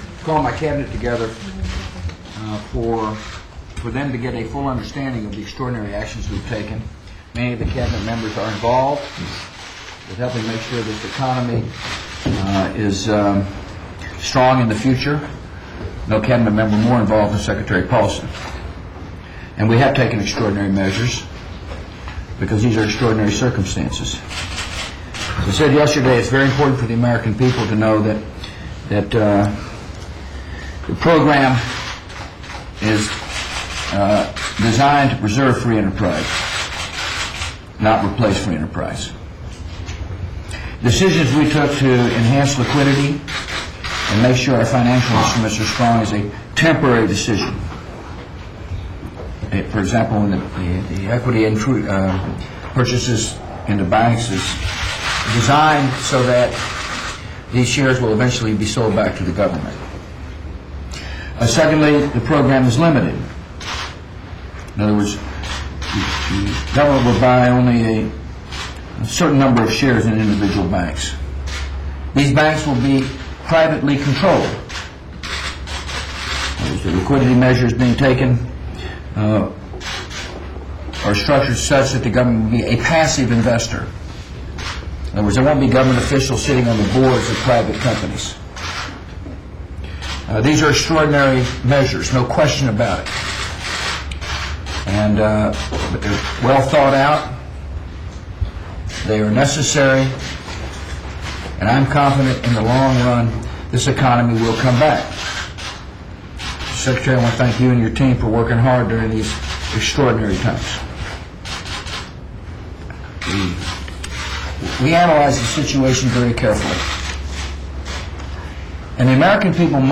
U.S. President George W. Bush speaks to the press with his cabinet to discuss the nation's economy